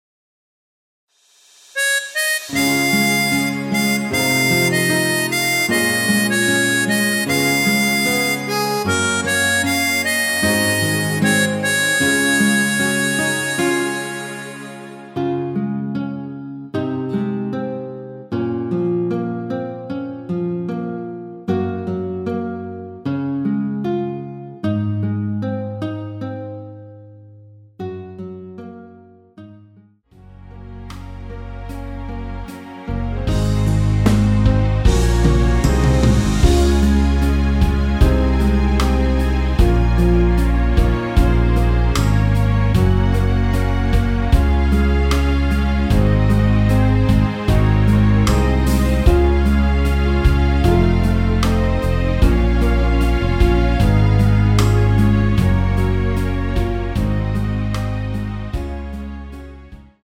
내린 MR 입니다.
Db
◈ 곡명 옆 (-1)은 반음 내림, (+1)은 반음 올림 입니다.
앞부분30초, 뒷부분30초씩 편집해서 올려 드리고 있습니다.